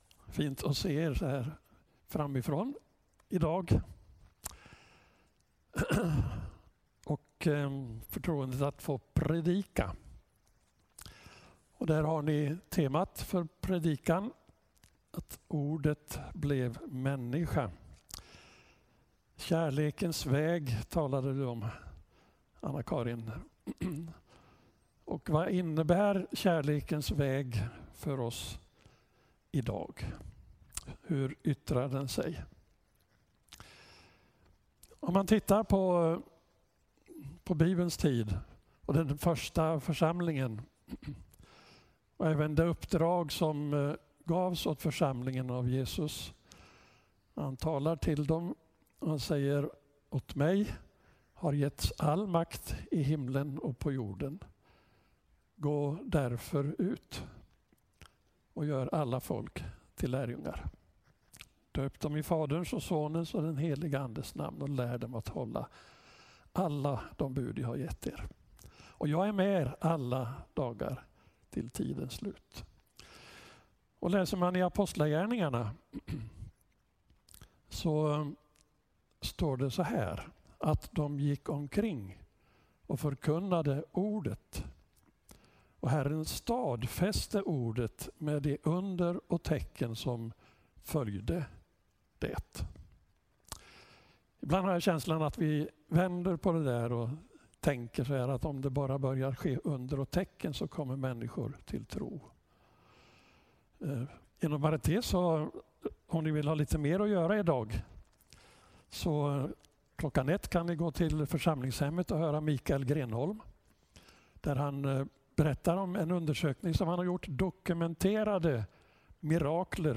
Gudstjänst 23 februari 2020